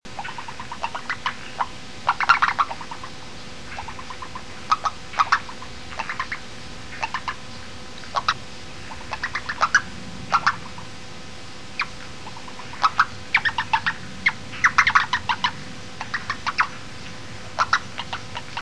物種名稱 長腳赤蛙 Rana longicrus
錄音地點 新北市 土城區 土城彈藥庫
錄音環境 區內靜止水域旁
行為描述 鳴叫